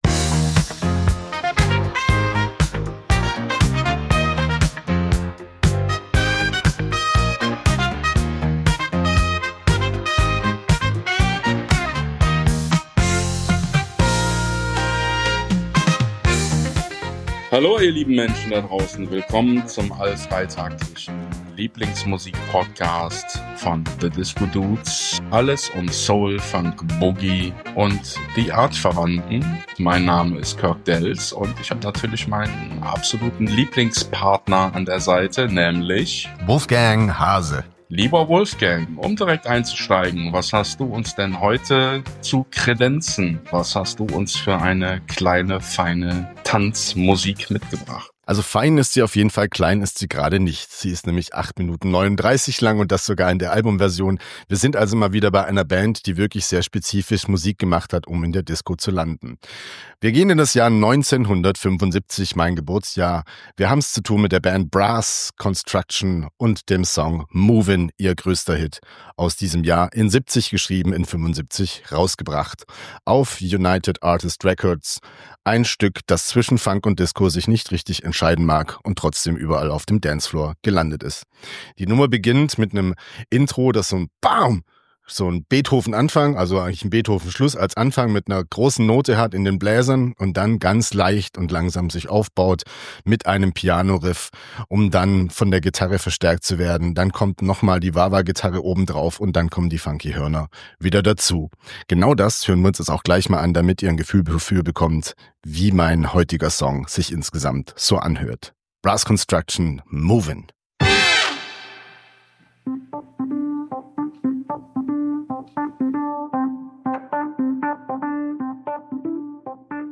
Philly meets Brooklyn mit doch so ähnlichen Sounds of funky Disco!